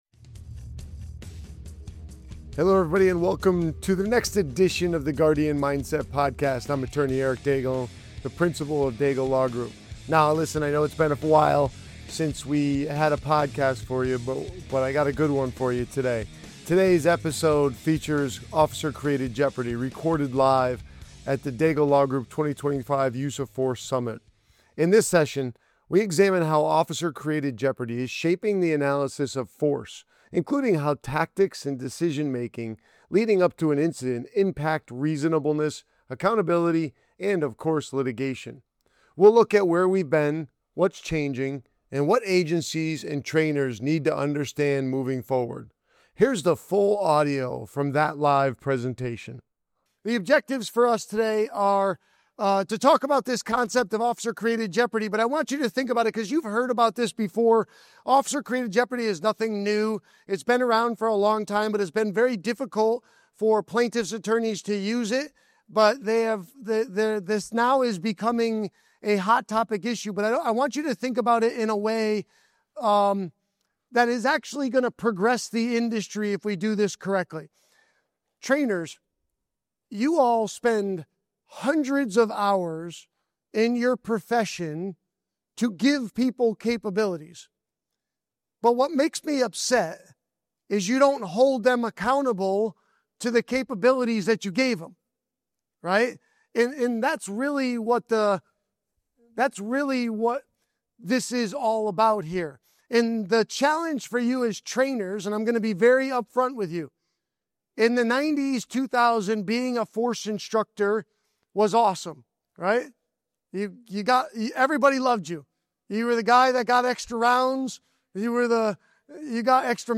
This episode of the Guardian Mindset Podcast was recorded live as the opening keynote at the 2025 Use of Force Summit, setting the tone for a critical discussion on how modern courts, communities, and agencies evaluate police use of force. The episode examines the growing legal and operational focus on Officer Created Jeopardy and why decisions made well before force is used are now central to accountability, training, and liability.